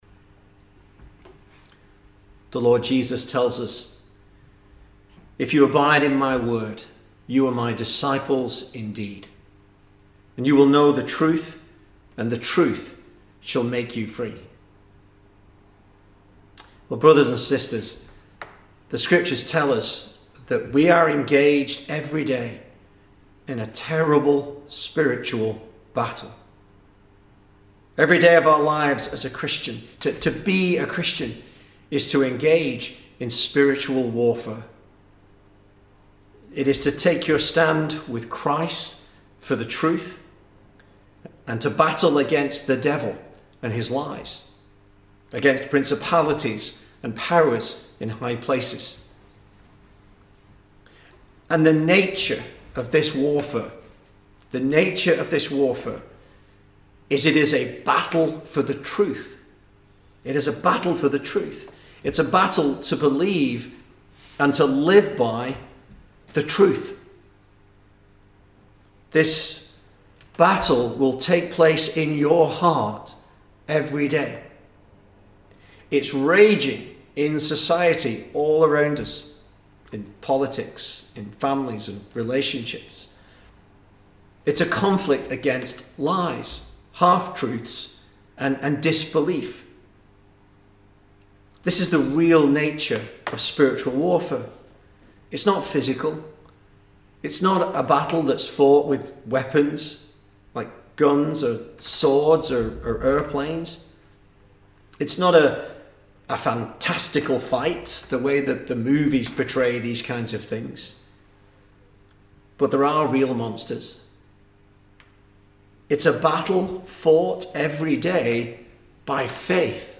2020 Service Type: Sunday Evening Speaker